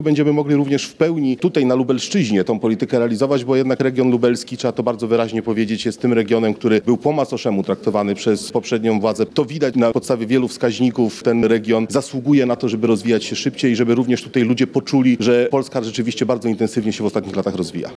Lokalni kandydaci do rad gminy, powiatu i sejmiku wojewódzkiego zostali przedstawieni podczas spotkania Prawa i Sprawiedliwości w Hrubieszowie z udziałem sekretarza stanu w Kancelarii Prezesa Rady Ministrów Jacka Sasina.